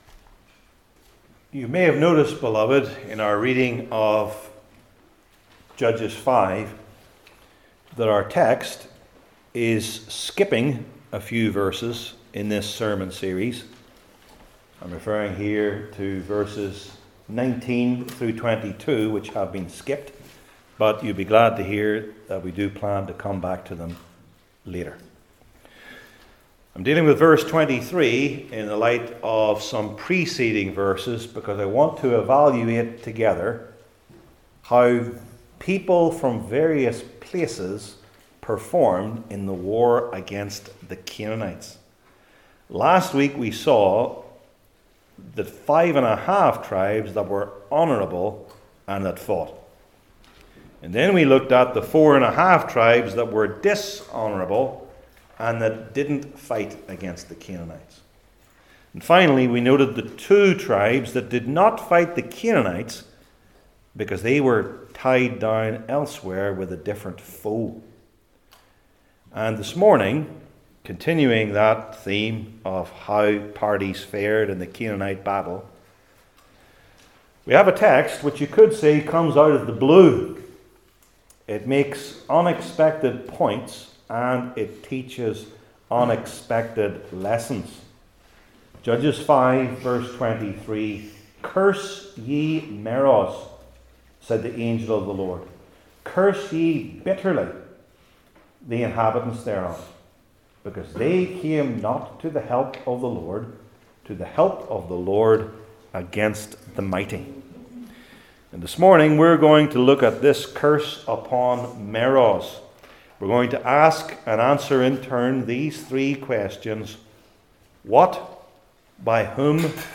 Old Testament Sermon Series I. What?